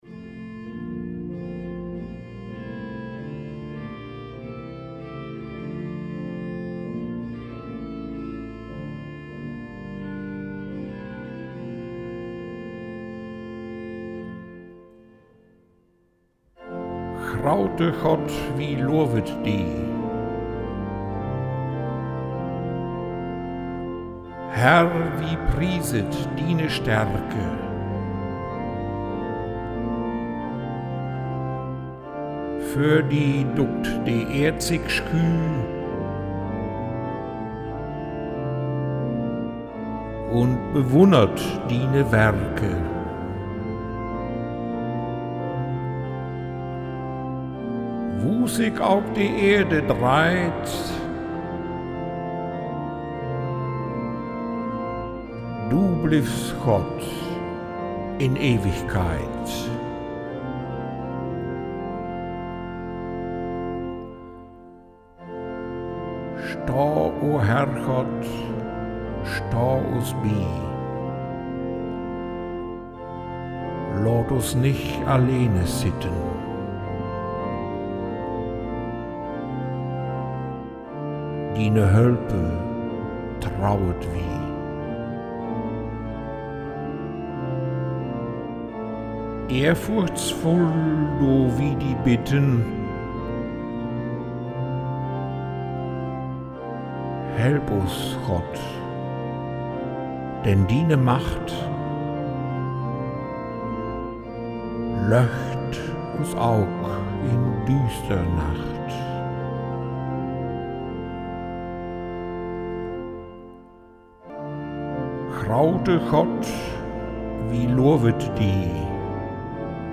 Alle Kerkengesänge